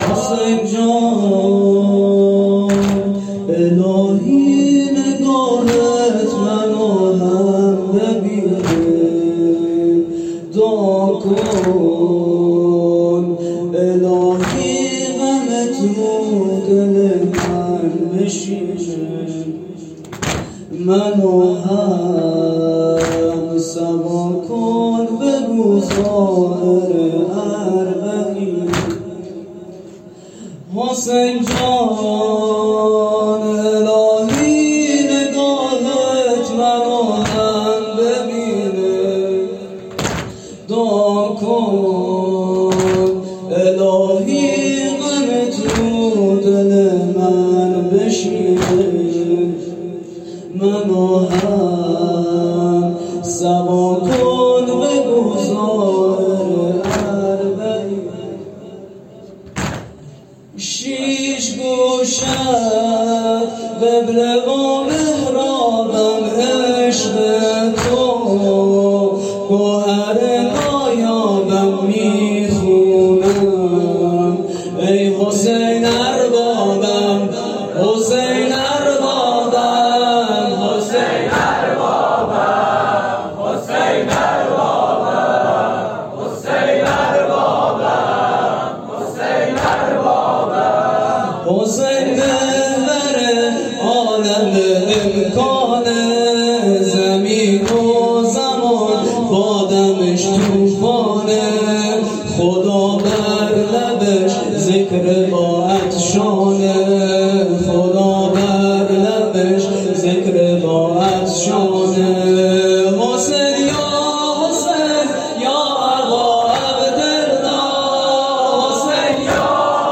هیئت صادقیون زابل
واحد(حسین جان الهی نگاهت من و هم ببینه
جلسه هفتگی|۳۰شهریور۱۴۰۰|۱۴صفر۱۴۴۳